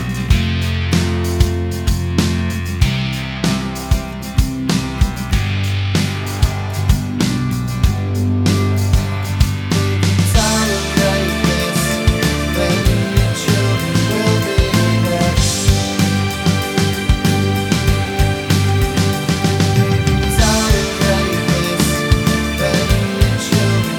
no Backing Vocals Indie / Alternative 4:51 Buy £1.50